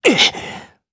Zafir-Vox_Damage_jp_02.wav